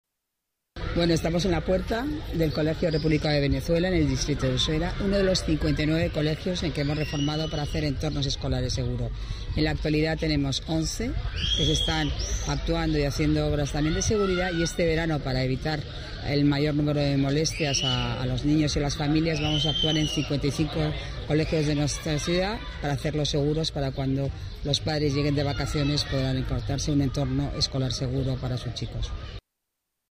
Declaraciones de la delegada de Obras y Equipamientos, Paloma García Romero